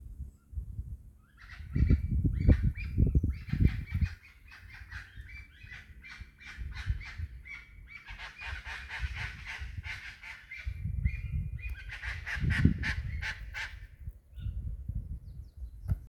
Chaco Chachalaca (Ortalis canicollis)
Location or protected area: Río Ceballos
Condition: Wild
Certainty: Observed, Recorded vocal
Charata.mp3